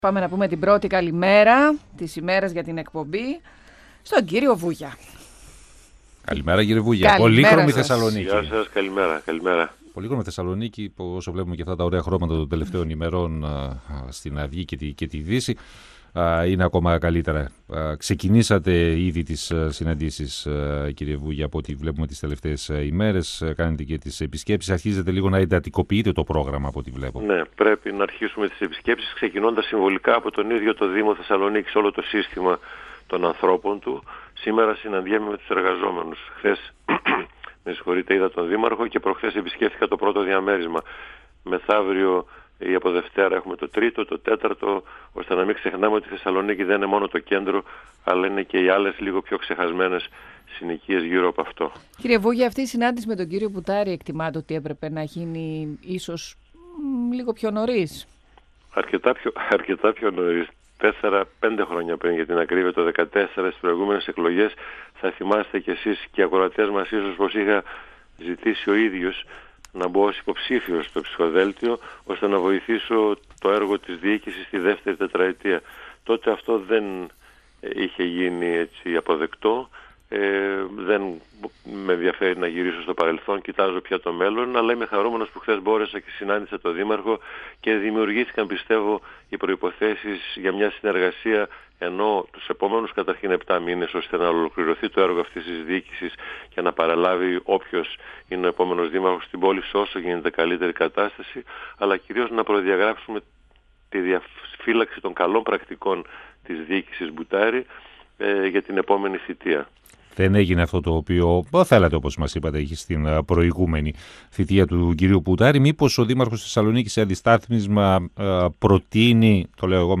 Φυσική συνέχεια της «Πρωτοβουλίας» είναι η «ΠΟΛΗχρωμη Θεσσαλονίκη», επισήμανε ο υποψήφιος δήμαρχος Σπύρος Βούγιας, μιλώντας στον 102FM του Ραδιοφωνικού Σταθμού Μακεδονίας της ΕΡΤ3. Ο κ. Βούγιας αναφέρθηκε στη συνάντησή του με τον δήμαρχο, Γιάννη Μπουτάρη, αναγνώρισε ότι οι συζητήσεις που πραγματοποιούνται για το ενδεχόμενο συνεργασίας με άλλες παρατάξεις συναντούν μεγάλα εμπόδια και πρακτικά δεν οδηγούν πουθενά, ενώ πρόσθεσε ότι δεν πρέπει να χαθεί άλλος χρόνος.